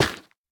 Minecraft Version Minecraft Version latest Latest Release | Latest Snapshot latest / assets / minecraft / sounds / block / nylium / break5.ogg Compare With Compare With Latest Release | Latest Snapshot